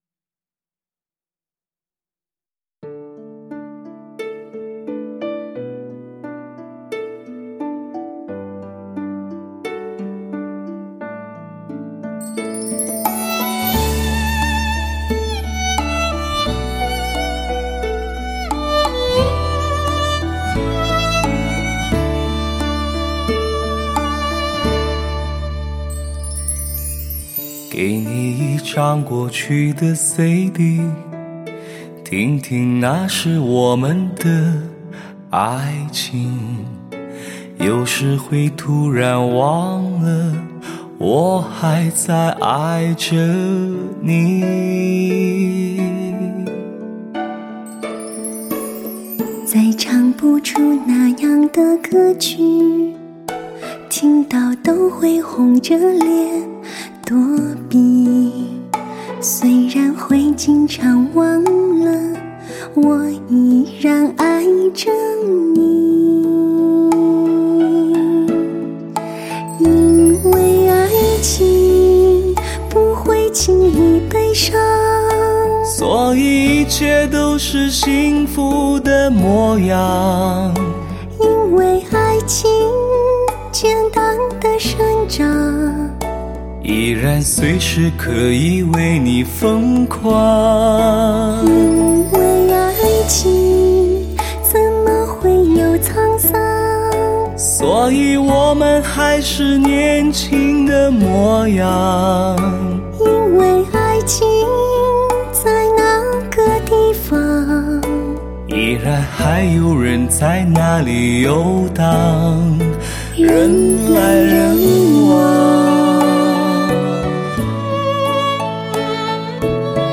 再现极富视听效果的发烧靓声，看似平凡而不平凡的制作……